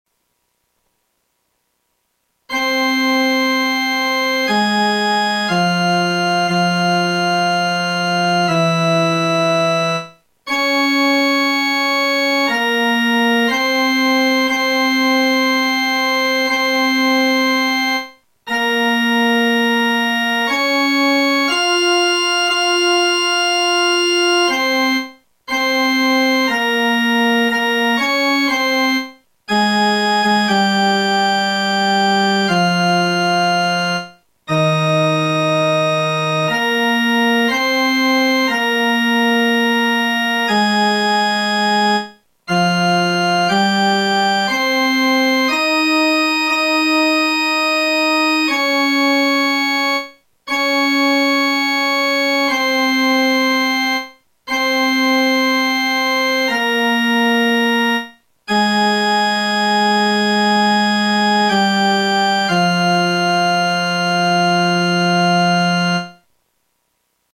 Nas procissões da sexta-feira santa, em Botucatu, a Verônica cantava o lamento abaixo, com música de Alfredo Franklin de Mattos (1923) e versos sacros ("O vos omnes qui transitis per viam attendite e videte si est dolor, sicut dolor meus" - Ó vos que passais pela estrada, dizei se já viste dor maior que a minha)